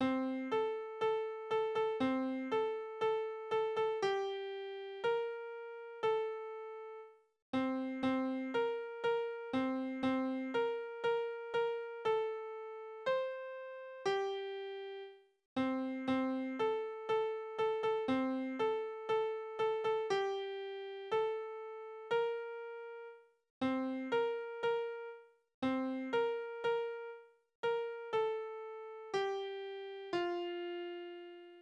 Kinderlieder: Bub und Spinne
Tonart: F-Dur
Taktart: 4/4
Tonumfang: Oktave
Besetzung: vokal